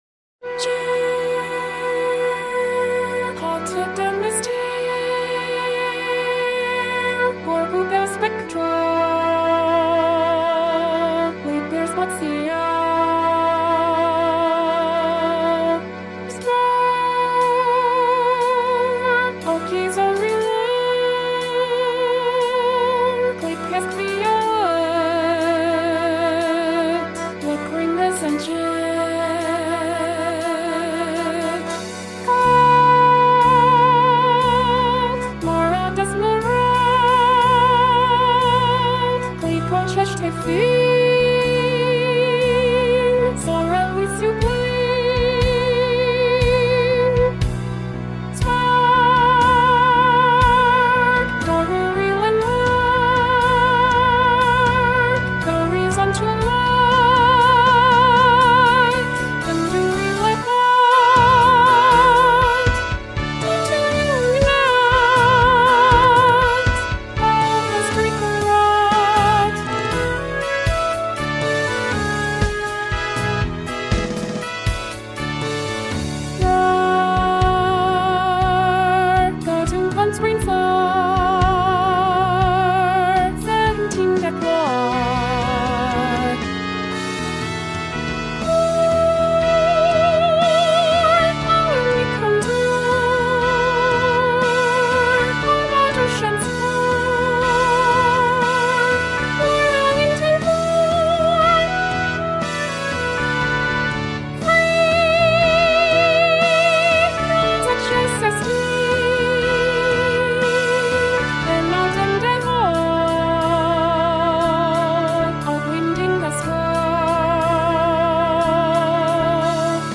Pacifica_feat.Eleanor_(virtual_singer).mp3